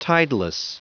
Prononciation du mot tideless en anglais (fichier audio)
Prononciation du mot : tideless